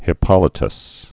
(hĭ-pŏlĭ-təs)